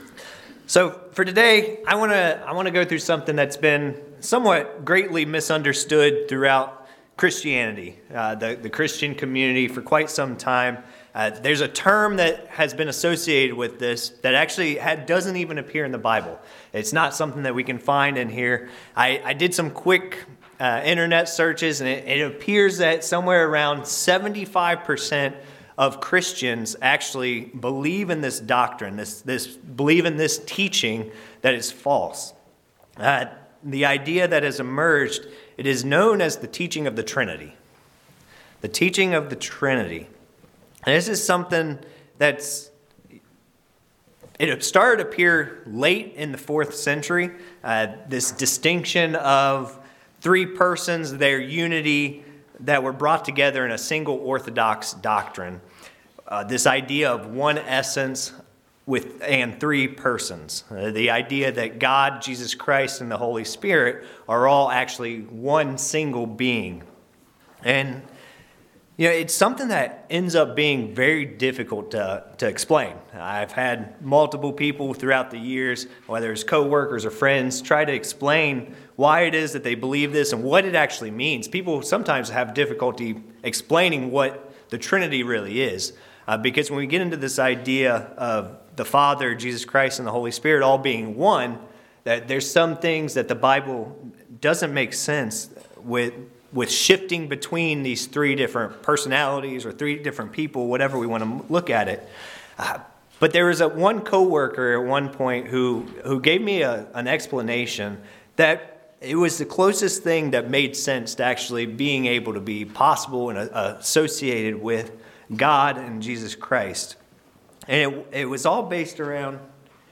Sermons
Given in Ft. Wayne, IN